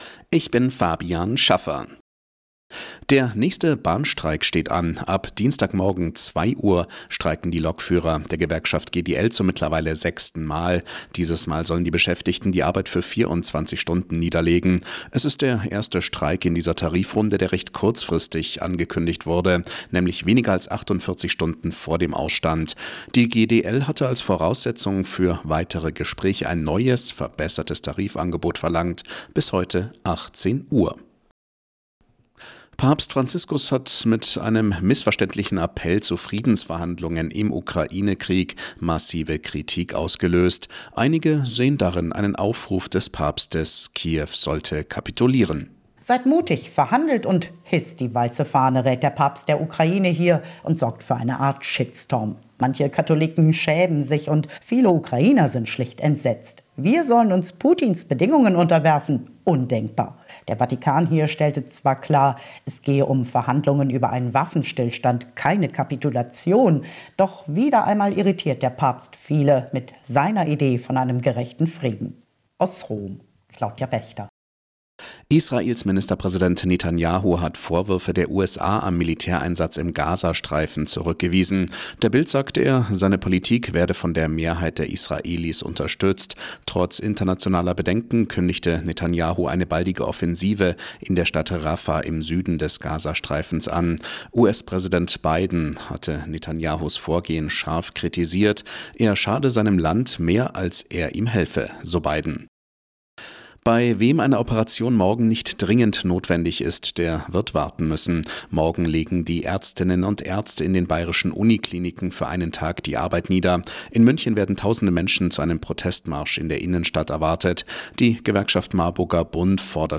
Die aktuellen Nachrichten von Radio Arabella - 11.03.2024